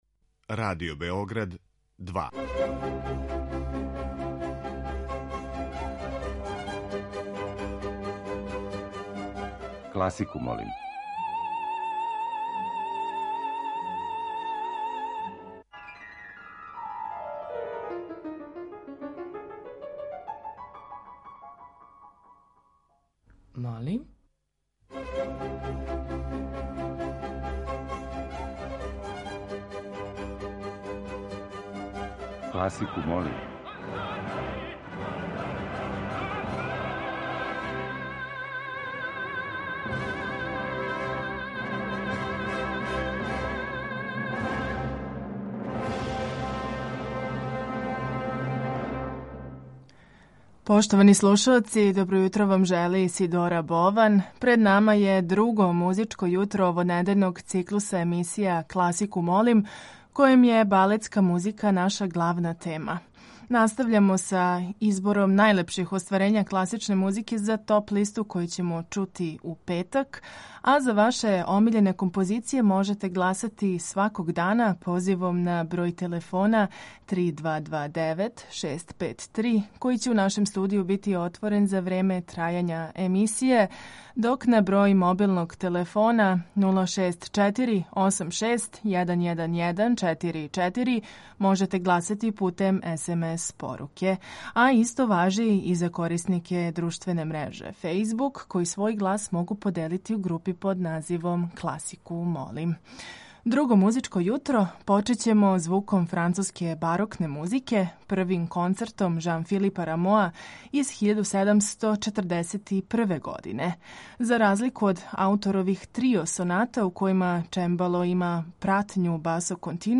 Тема циклуса носи наслов „Балетска музика'.